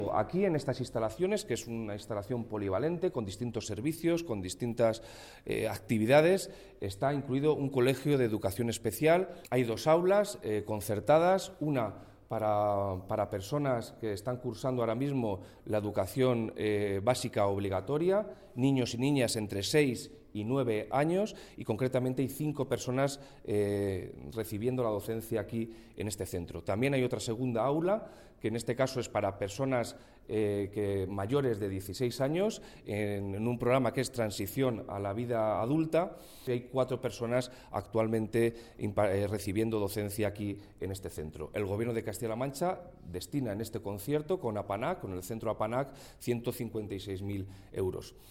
El delegado de la Junta en Guadalajara, Alberto Rojo, habla del concierto de dos aulas TEA en el centro de APANAG.